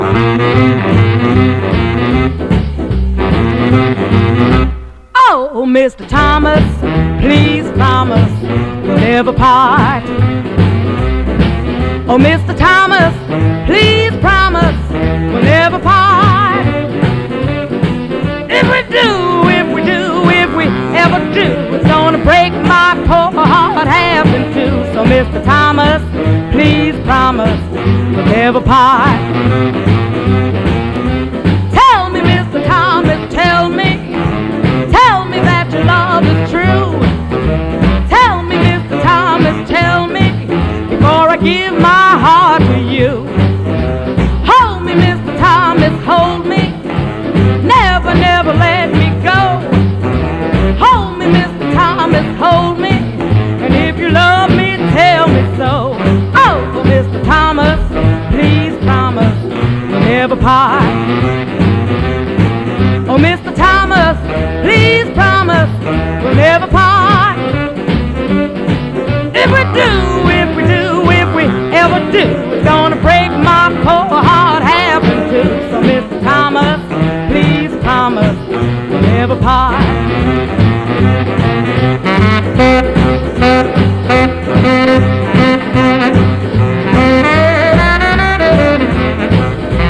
ジャイヴ～ジャンプ・ブルーズ～リズム＆ブルースな女性ヴォーカル・ナンバーを集めた名コンピ！